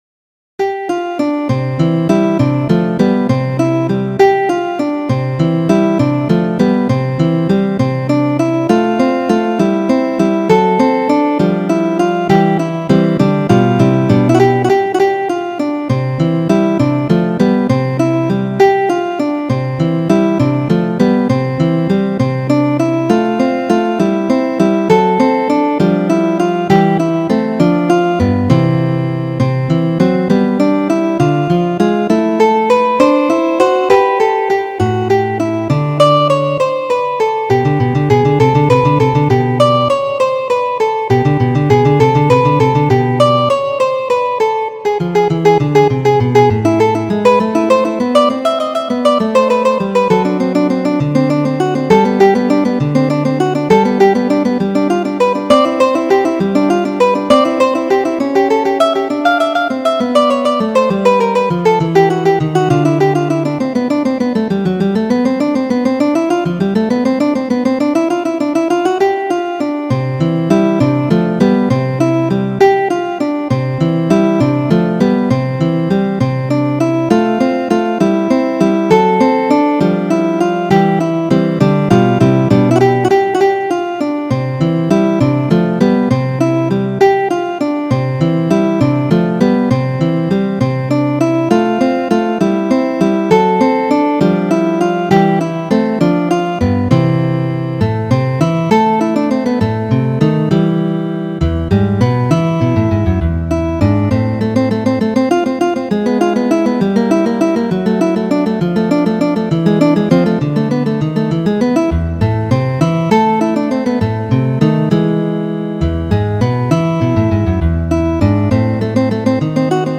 Free Sheet music for Guitar
Guitar  (View more Intermediate Guitar Music)
Classical (View more Classical Guitar Music)